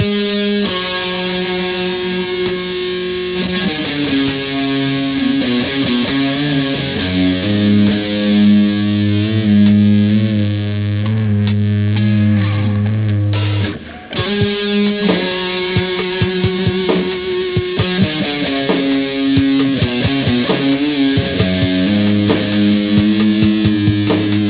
These riffs were recorded in mono to minimize file size.
I was trying to get a dark sound across.